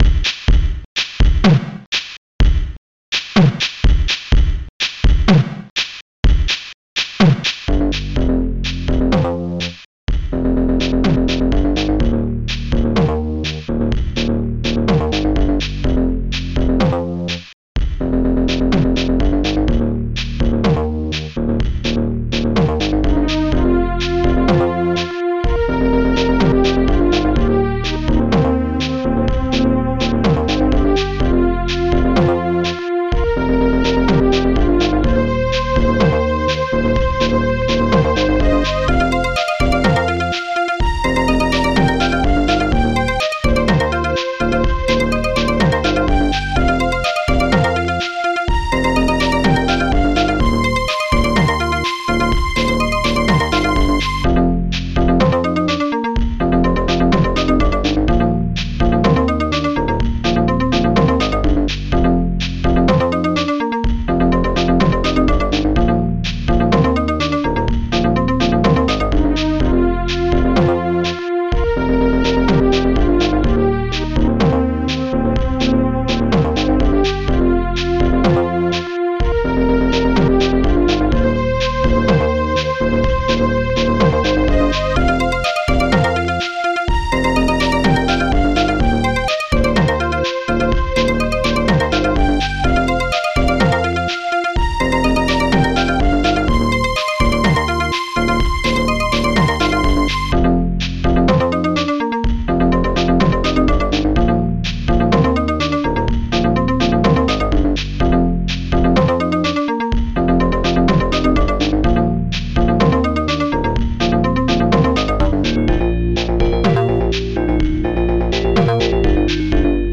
st-01:bassdrum3 st-02:snare7 st-01:shaker st-02:hitbass st-01:strings3 st-01:perco st-02:glockenspiel st-02:friday st-01:licks